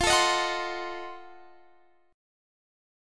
Listen to F7b9 strummed